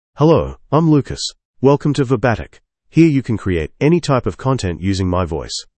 MaleEnglish (Australia)
Lucas is a male AI voice for English (Australia).
Voice sample
Listen to Lucas's male English voice.
Male
Lucas delivers clear pronunciation with authentic Australia English intonation, making your content sound professionally produced.